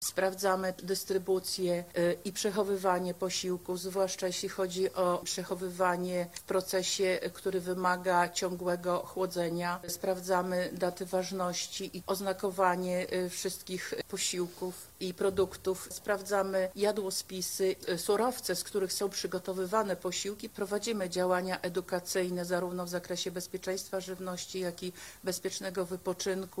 Obiektom wypoczynkowym przyjrzą się Stacje Sanitarno – Epidemiologiczne – zapowiedziała inspektor Sanitarny dr Maria Siewko.